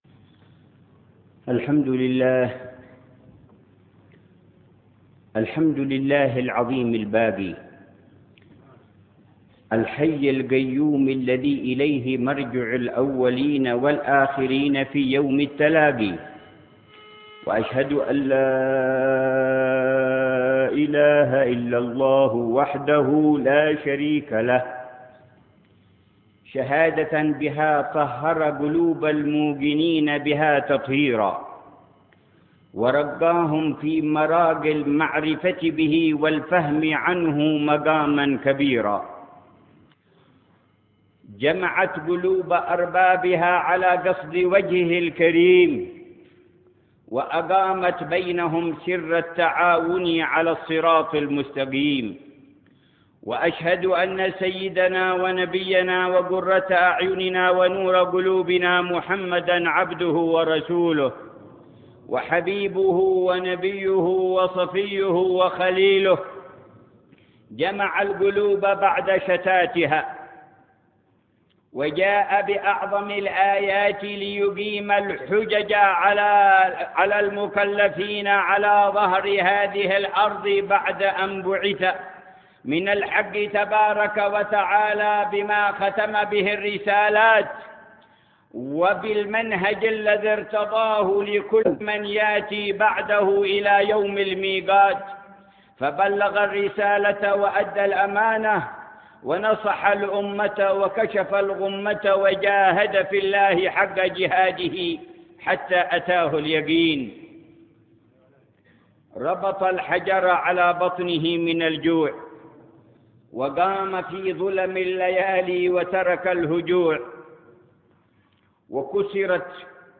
خطبة جمعة في افتتاح جامع الصفاء بصنعاء
خطبة جمعة للحبيب عمر بن حفيظ في افتتاح جامع الصفاء بصنعاء تاريخ 28 شعبان 1429هـ.